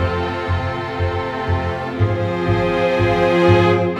Rock-Pop 11 Strings 07.wav